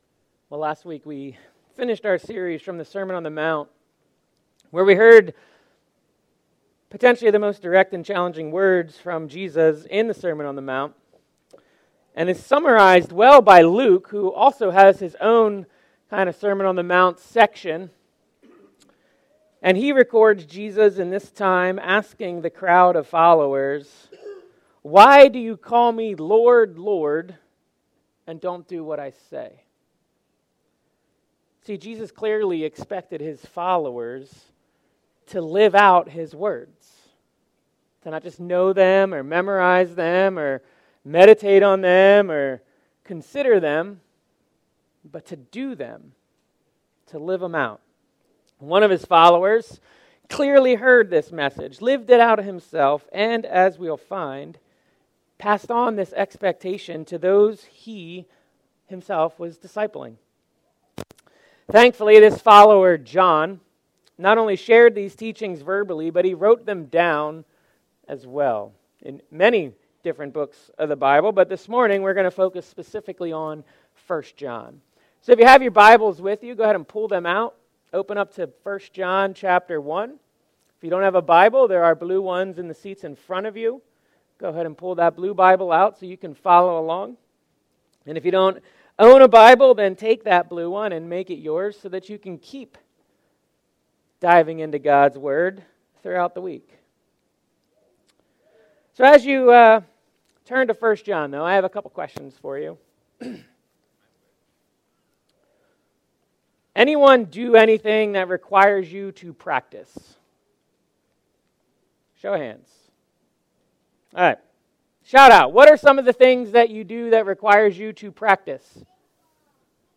Finland Mennonite Church - Living 1st John - Sermon Series